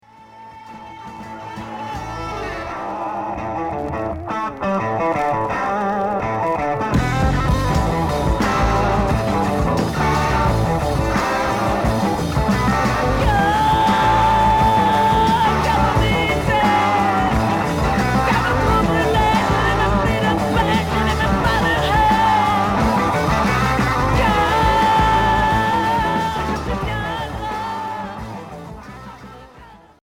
Heavy rock psychédélique Unique 45t retour à l'accueil